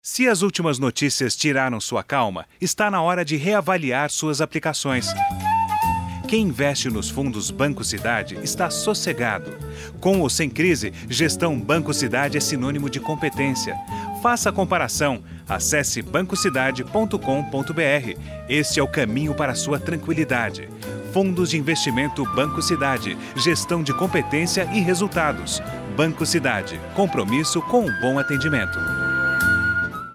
spot_tranquilidade_bc_eldorado.mp3